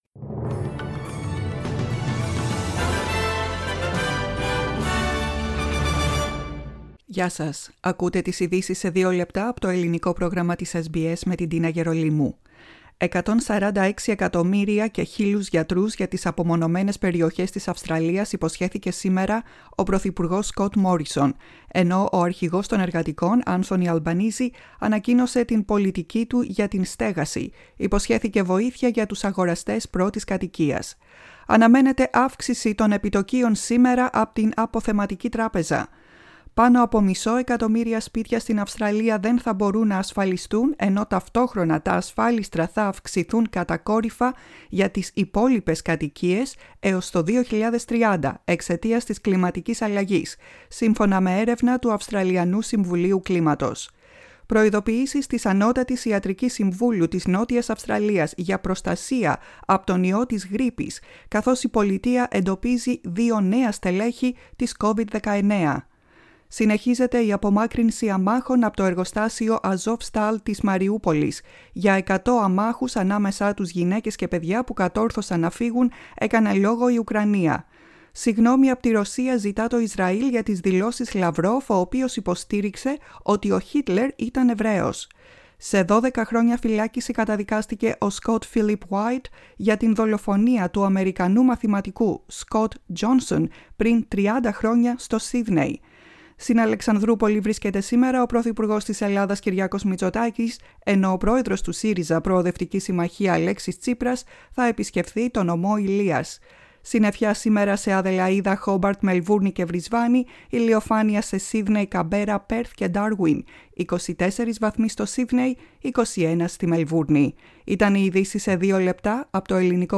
Flash News στα Ελληνικά: Δευτέρα 02.05.22 Source: SBS GREEK